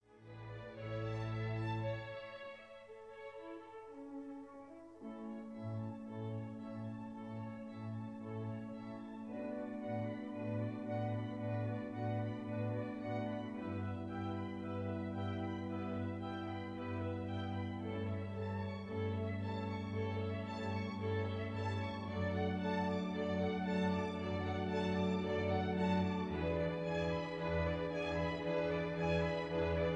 A stereo recording made in Walthamstow Assembly Hall 1958